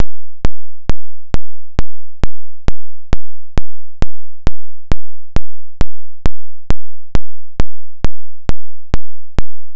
It's a sequence of positive pulses (actually a single polarity sawtooth waveform), and it can be saved to a memory stick or recorded onto a CD.  The file is only short (about 9 seconds), and would normally be set to repeat.